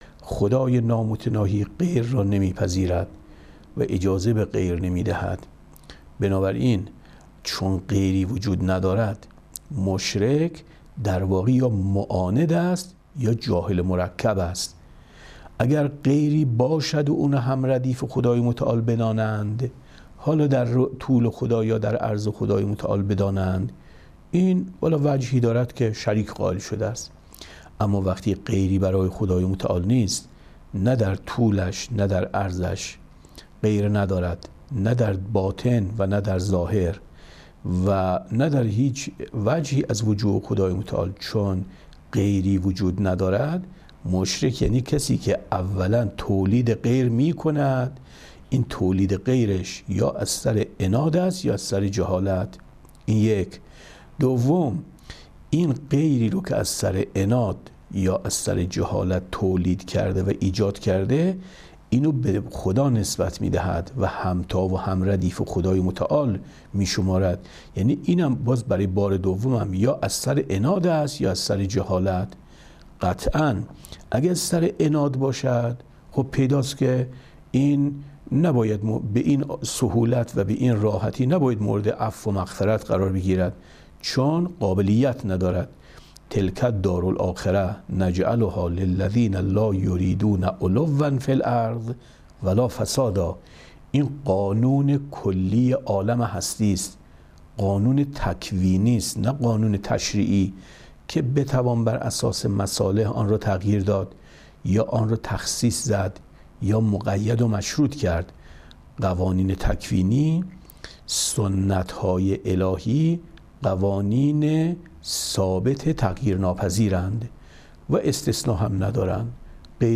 گزیده شرح اصول کافی ـ درس 179